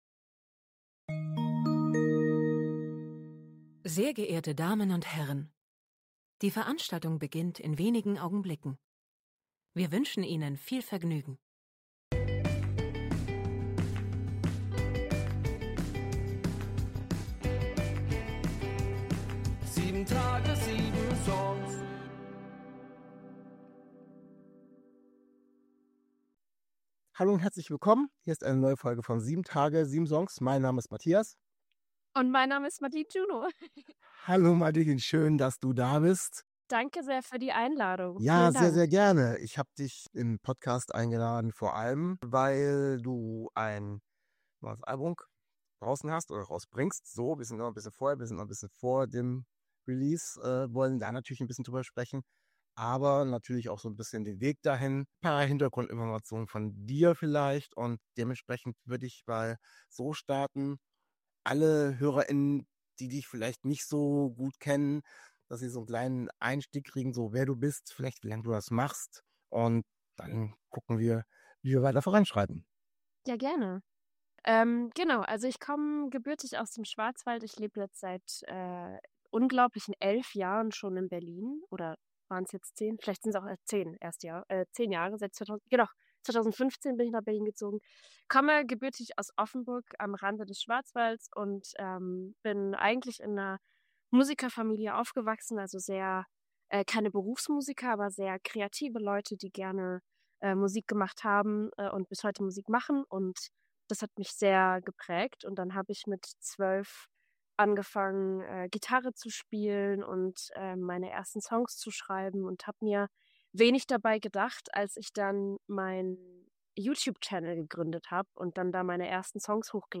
Letzte Episode #5.23 Zu Gast: Madeline Juno 21. Juni 2025 Nächste Episode download Beschreibung Kapitel Teilen Abonnieren Zu Gast ist Madeline Juno. Wir sprechen über ihre Musik und über den ersten Part ihrer Konzept Alben "Anomalie".